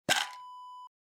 Pill Container Open Wav Sound Effect
Description: The sound of opening the lid of a pill container
Properties: 48.000 kHz 24-bit Stereo
A beep sound is embedded in the audio preview file but it is not present in the high resolution downloadable wav file.
Keywords: pop, open, opening, lid
pill-container-open-preview-1.mp3